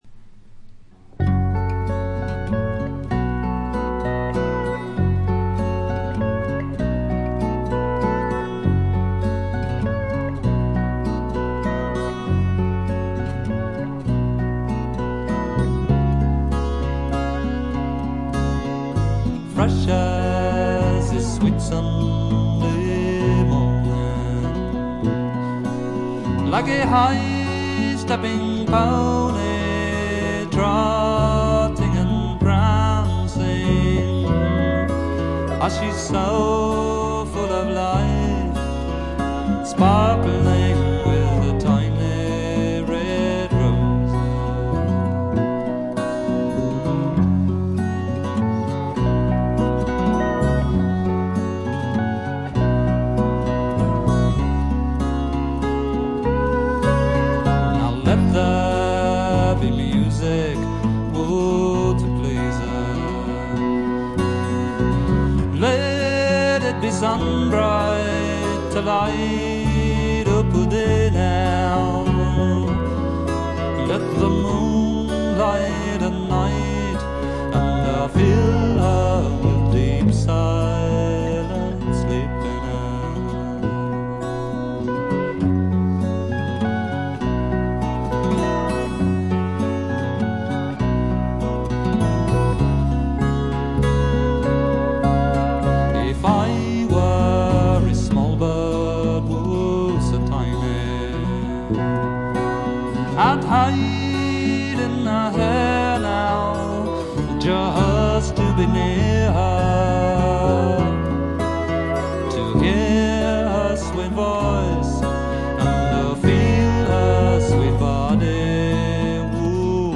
チリプチがそこそこ。散発的なプツ音も少し。
英国フォーク度70%、アメリカンな香り30%という感じでしょうか。
試聴曲は現品からの取り込み音源です。
guitar, piano, vocals